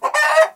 angryloudcluck2.wav